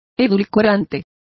Complete with pronunciation of the translation of sweetener.